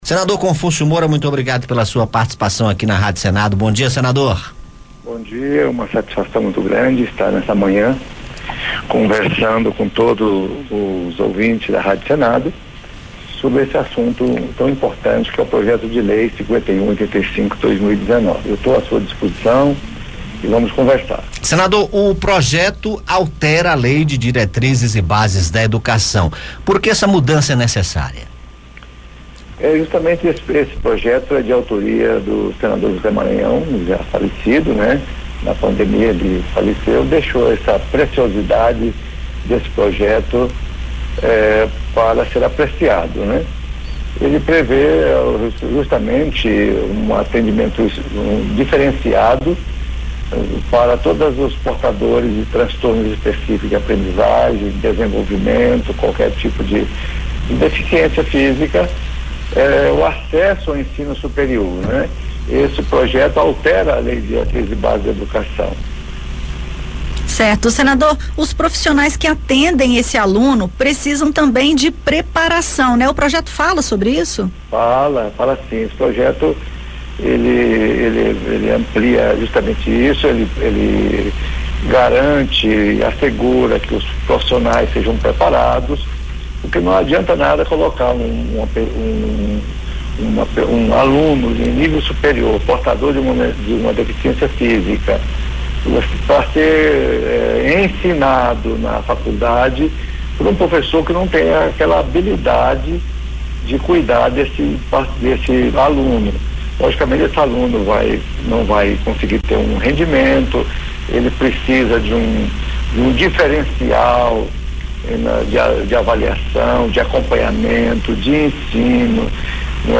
Em entrevista ao Conexão Senado, o senador Confúcio Moura (MDB-RO), relator do PL 5185/19, aprovado em abril na Comissão de Educação (CE), diz ser favorável ao projeto que prevê o oferecimento desse atendimento também ao ensino superior. Confúcio considera a mudança necessária e defende a preparação de profissionais no ensino superior para atender às especificidades e limitações desses alunos.